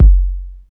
25.04 KICK.wav